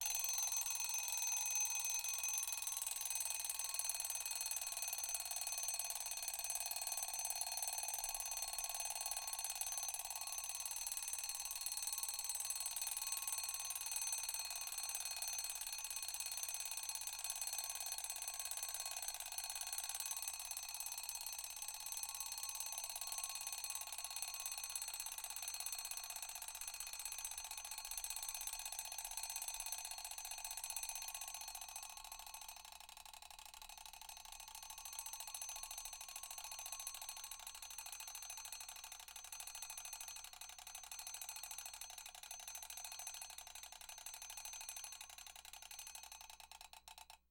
Mechanical Clock Ring
Home > Sound Effect > Alarms
Mechanical_Clock_Ring.mp3